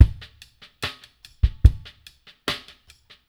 BOL LOFI 1-L.wav